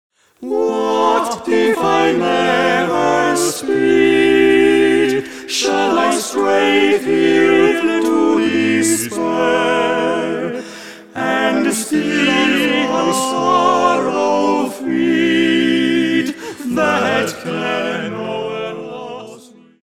grup vocal profesionist
doi tenori, un bariton si un bass.